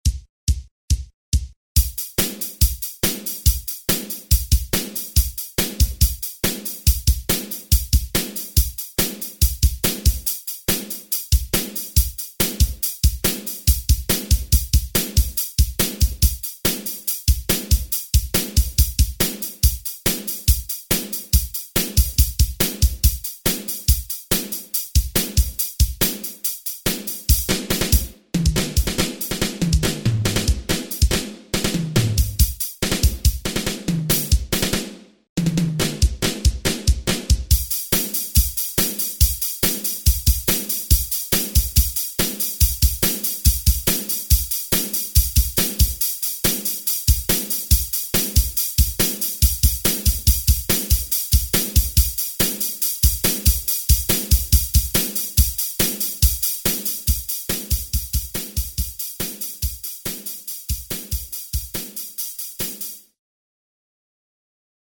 Rackmount expander derived from classic Proteus series based on percussives and drum samples.
Class: Drum Module
Synthesis: PCM rompler
kit 4